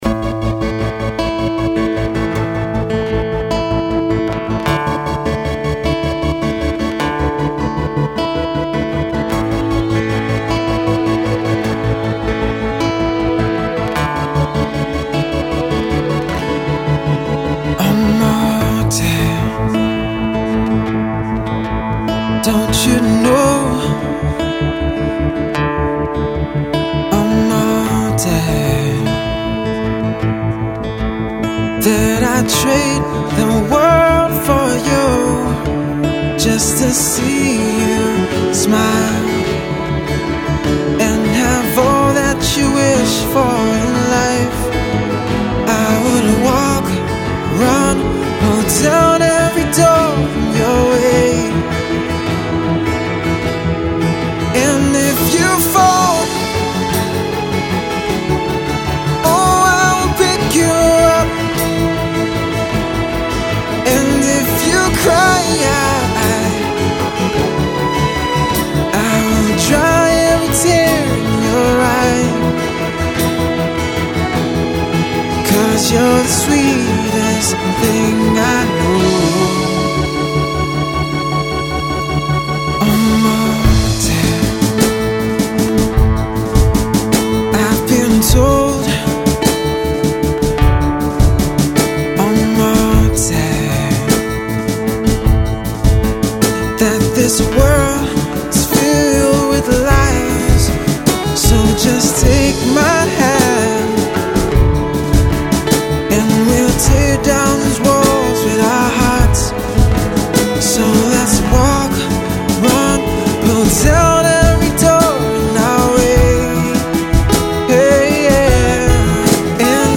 a beautiful love song laced with powerful emotion